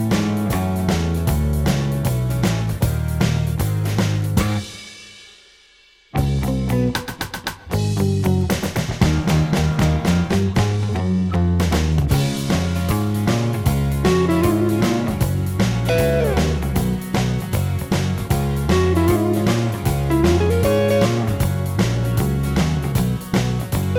One Semitone Down Rock 2:43 Buy £1.50